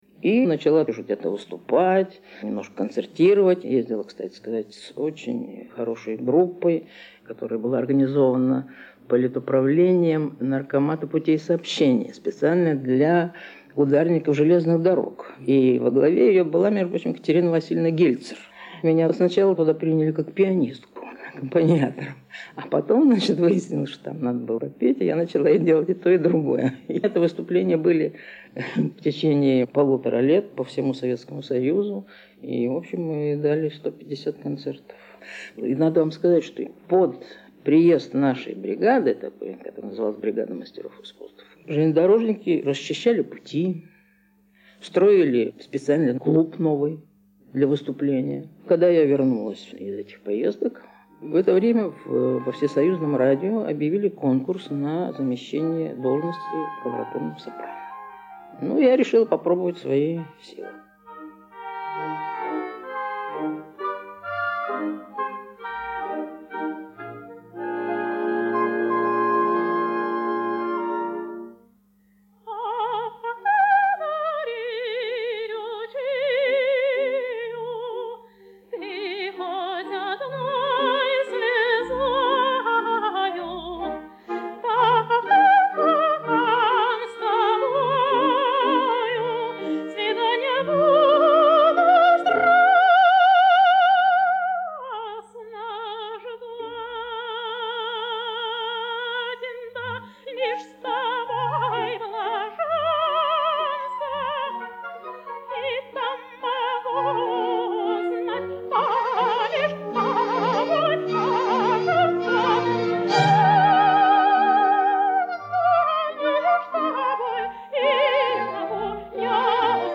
Фрагменты радиопередач с участием певицы.
Передача записана с УКВ